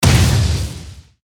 archer_skill_triangleshot_03_groundhit.ogg